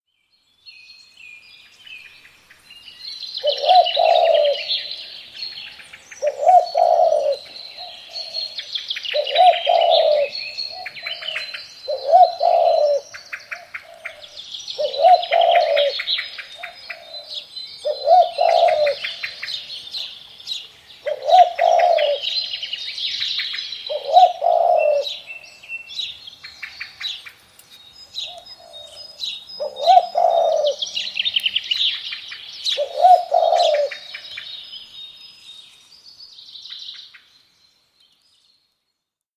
دانلود آهنگ یاکریم 1 از افکت صوتی انسان و موجودات زنده
جلوه های صوتی
دانلود صدای یاکریم 1 از ساعد نیوز با لینک مستقیم و کیفیت بالا